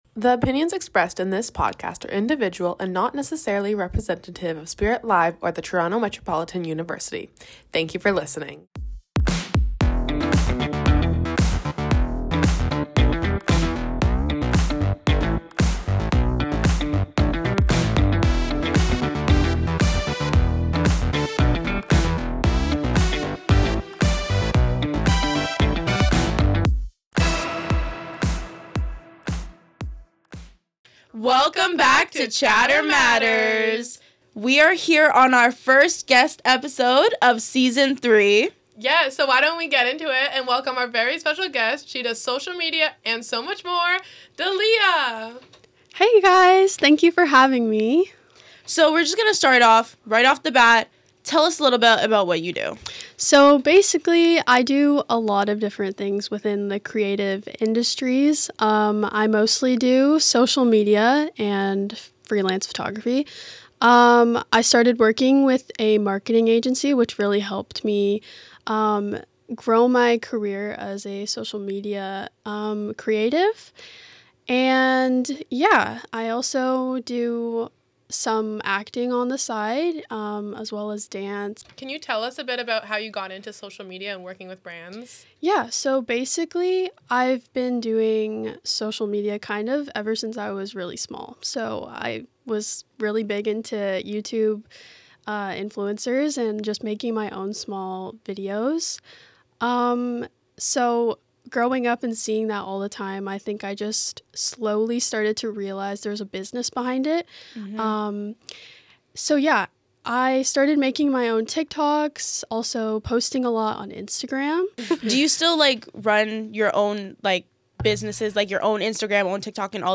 Each week get ready for engaging conversations, fresh insights, and plenty of laughs on Chatter Matters!